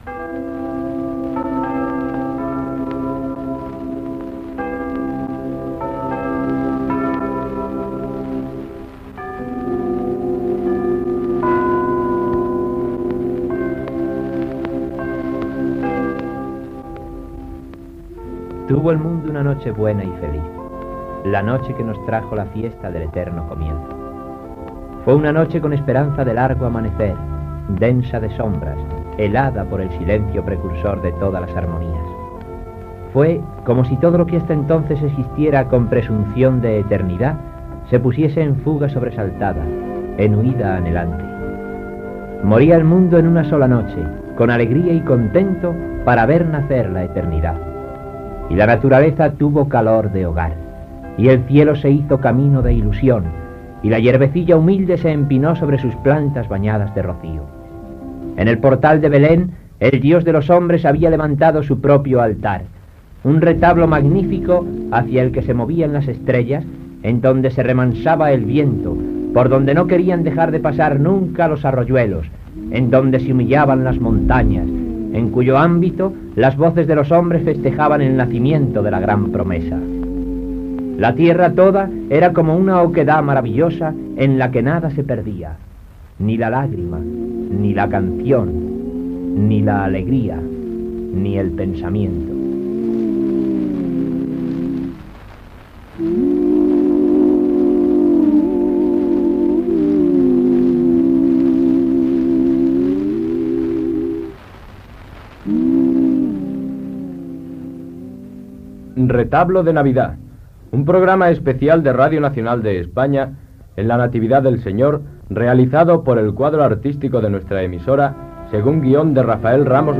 careta del programa, inici de la ficció sonora sobre la nit en la qual va néixer Jesús de Natzaret Gènere radiofònic Ficció